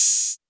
OPEN HAT [DRIVE THE BOAT].wav